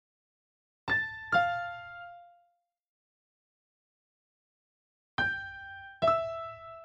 嘻哈陷阱钢琴140bpm
描述：最小的三角钢琴旋律 FL KEYS
Tag: 140 bpm Hip Hop Loops Piano Loops 1.16 MB wav Key : F